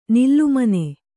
♪ nillumane